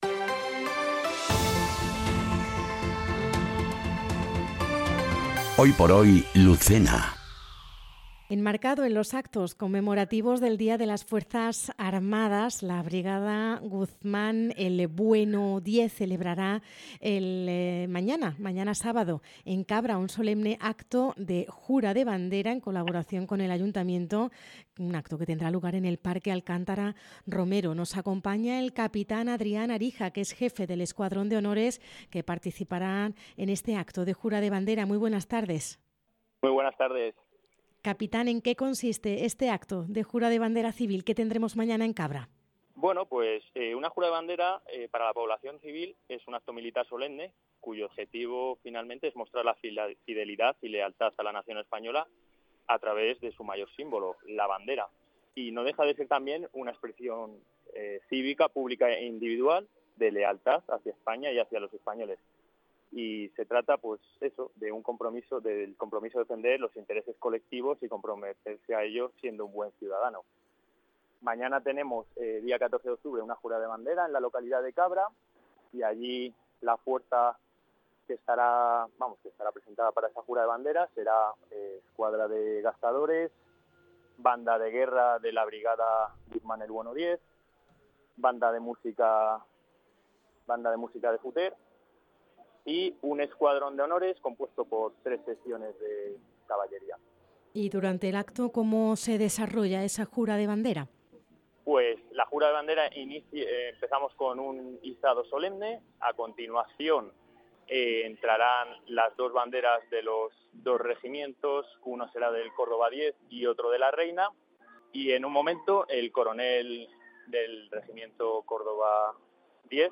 ENTREVISTA | Jura de Bandera Civil en Cabra